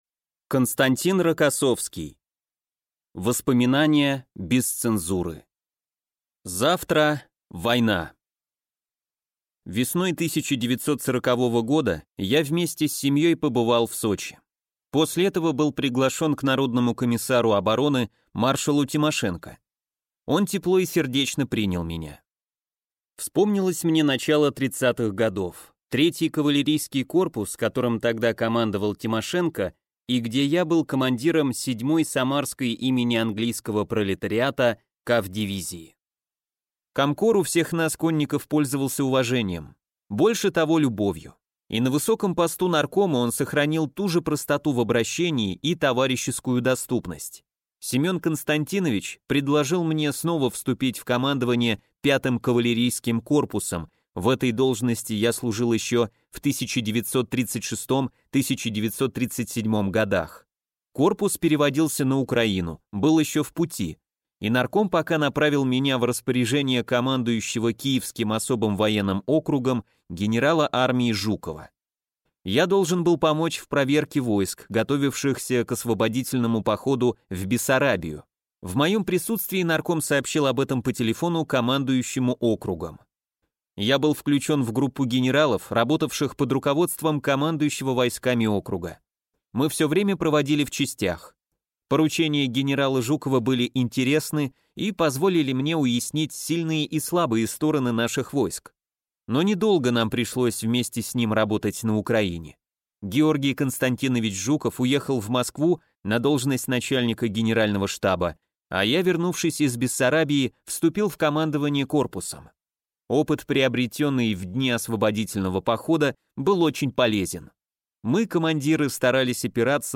Аудиокнига Воспоминания без цензуры | Библиотека аудиокниг